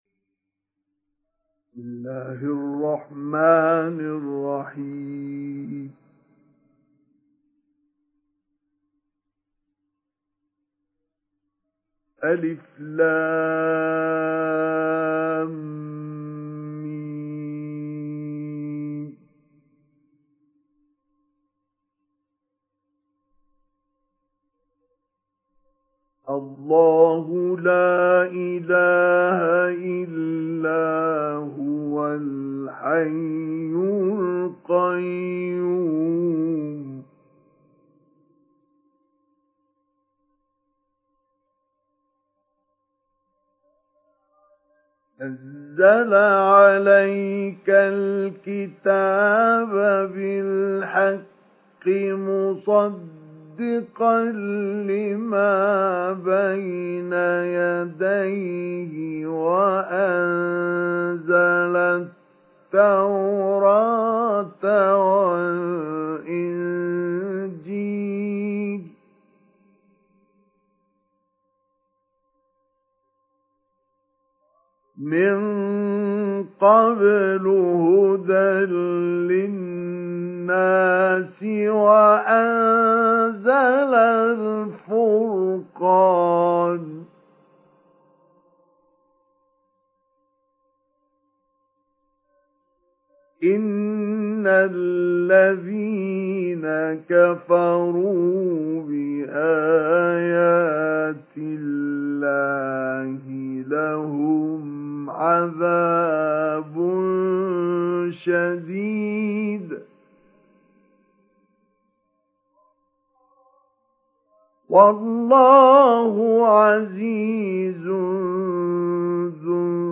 سُورَةُ ٓآلِ عِمۡرَانَ بصوت الشيخ مصطفى اسماعيل